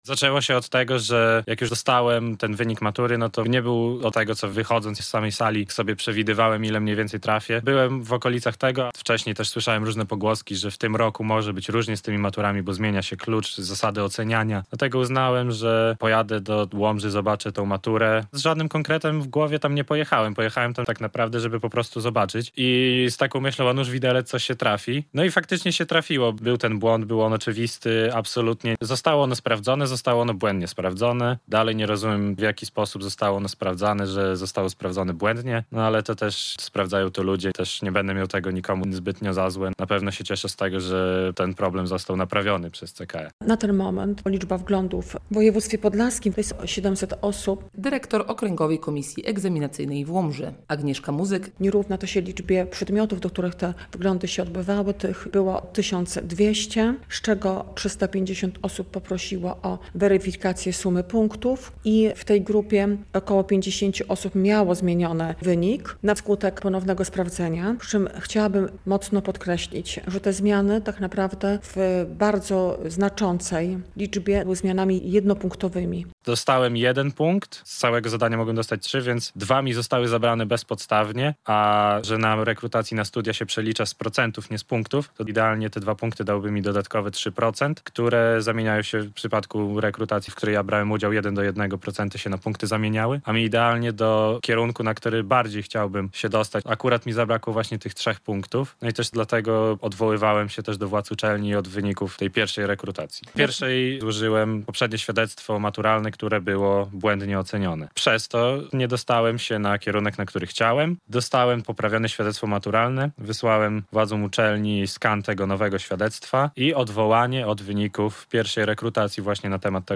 Odwołania od wyników egzaminów maturalnych i wyników rekrutacji na studia - relacja